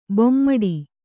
♪ bommaḍi